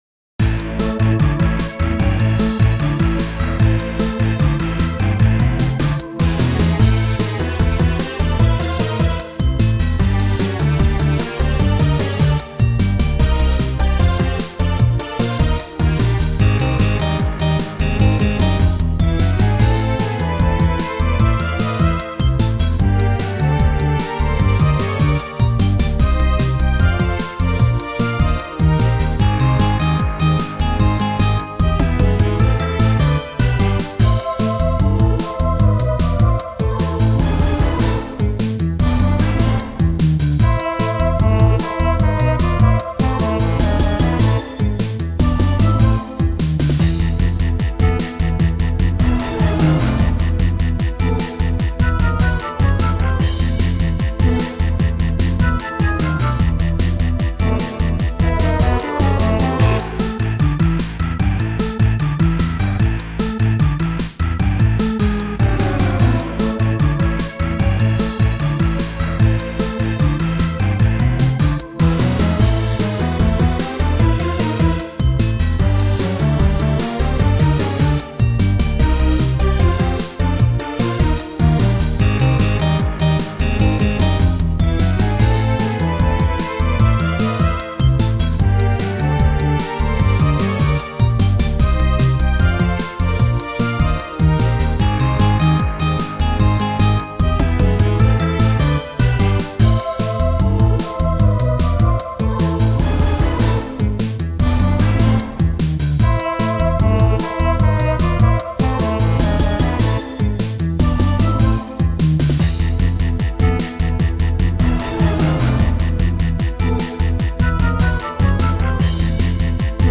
趣味でゲームBGMの適当な簡易アレンジを作って遊んでます。
再アレンジ。